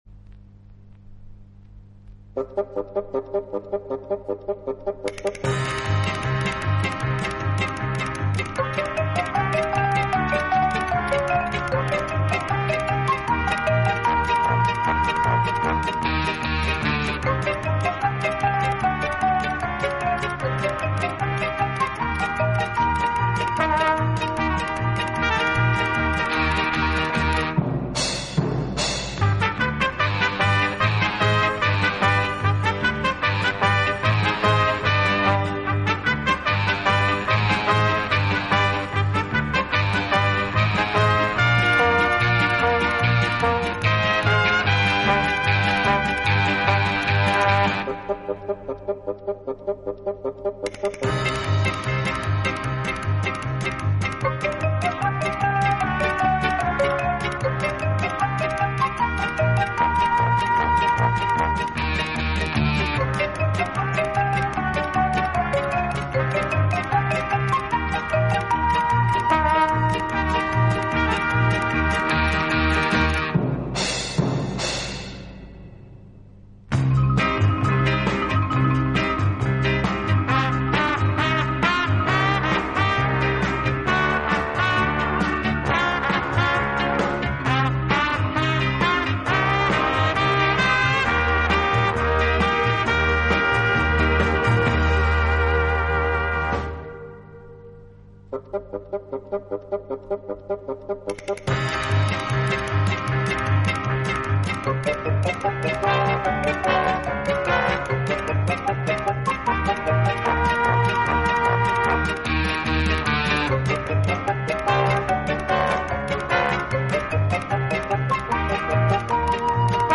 【轻音乐专辑】
演奏轻柔优美，特別是打击乐器的演奏，具有拉美音乐独特的韵味。